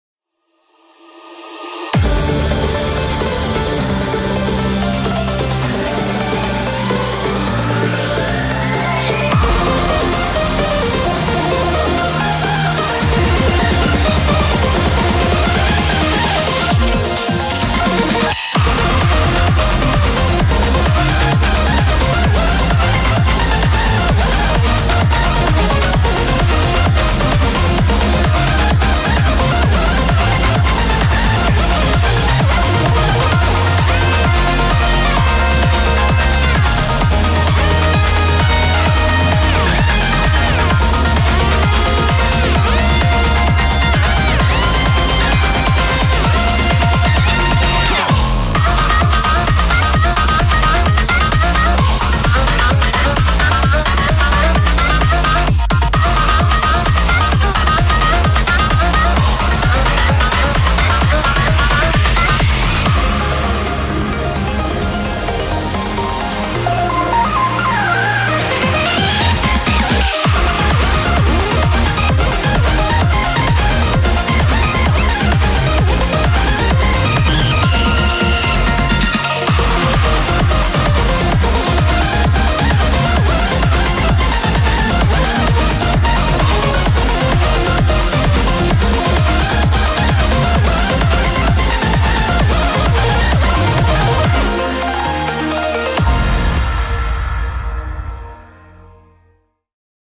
BPM130
Audio QualityPerfect (Low Quality)